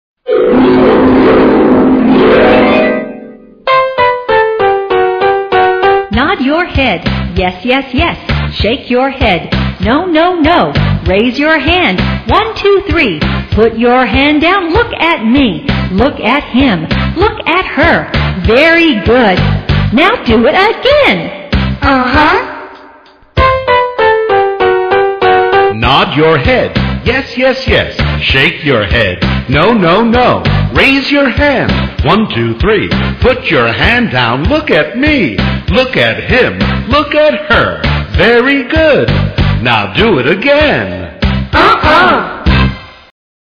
在线英语听力室英语儿歌274首 第143期:Nod your head的听力文件下载,收录了274首发音地道纯正，音乐节奏活泼动人的英文儿歌，从小培养对英语的爱好，为以后萌娃学习更多的英语知识，打下坚实的基础。